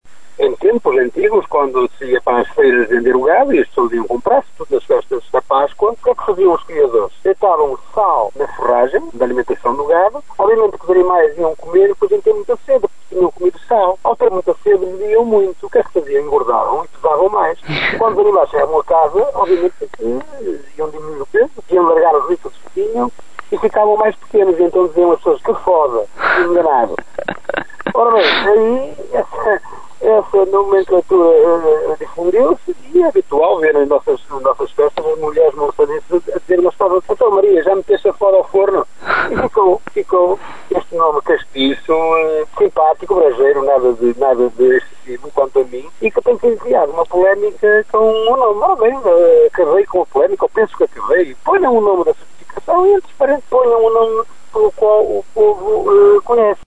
O nome por que é conhecido o cordeiro remonta a um truque antigo utilizado pelos criadores de cordeiro para conseguirem mais dinheiro pela venda dos animais. O presidente da Câmara de Monção, Augusto Domingues, conta a história.